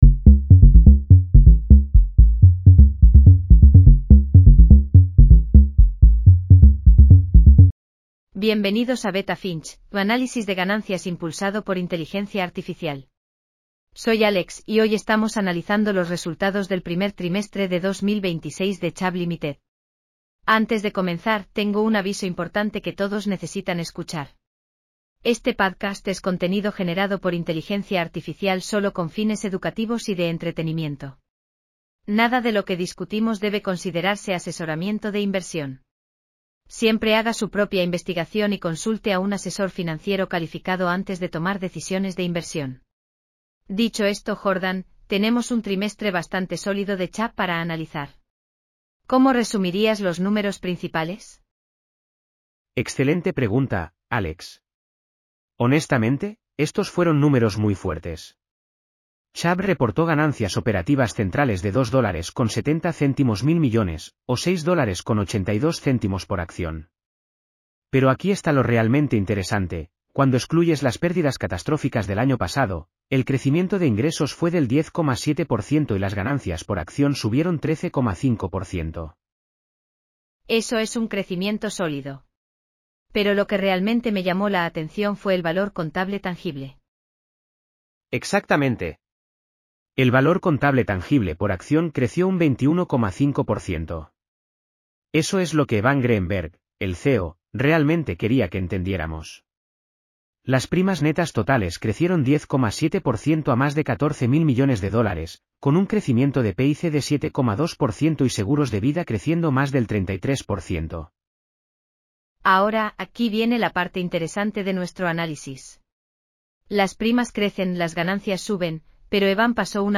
Bienvenidos a Beta Finch, tu análisis de ganancias impulsado por inteligencia artificial.